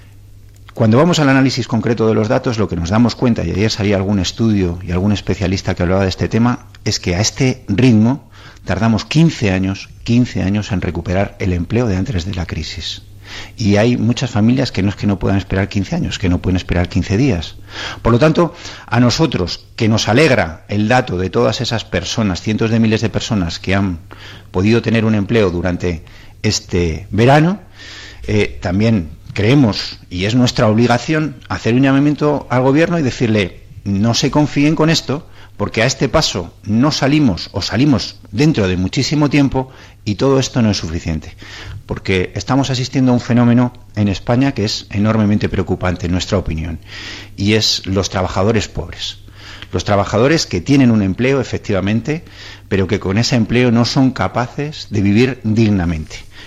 Fragmento de la entrevista de Antonio Hernando en Onda Cero en la que valora los datos de la EPA 24/10/2014